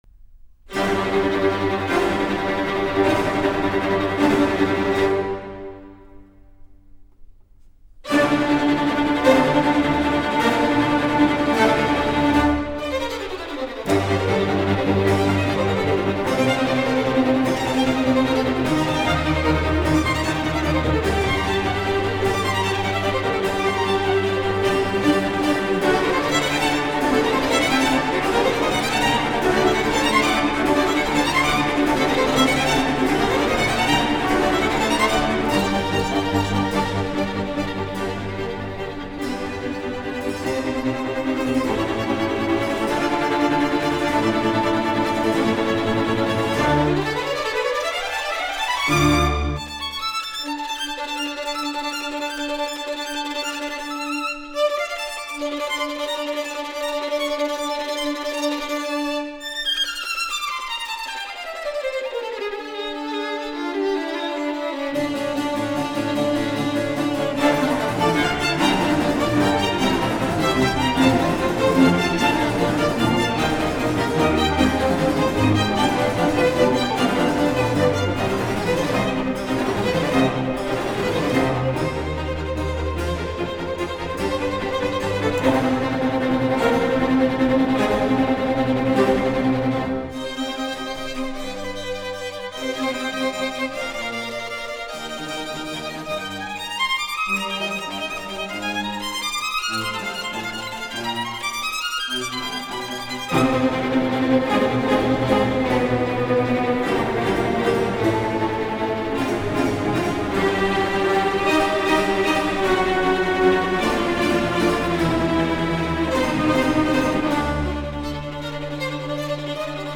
Первая часть отражает настроение и состояние лени и истомы.
Музыка звучит тихо и не очень быстро. Далее мы слышим голоса птиц: сначала кукушки,затем щегленка.
Его изображают все скрипки оркестра (включая солиста).
Но и это проходит: остаются одна солирующая скрипка и бас (его линия проводится виолончелью и аккомпанирующим органом).
Почти зримы потоки воды, низвергающиеся с неба, которые устремляются в разных направлениях, изображаемые гаммаобразными пассажами и арпеджиями, устремляющимися вверх и вниз.